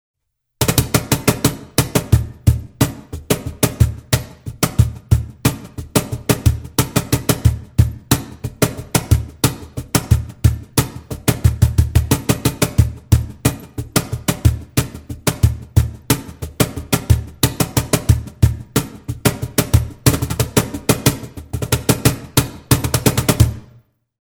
Cajon